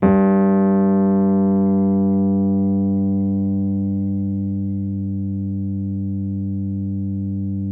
RHODES CL05R.wav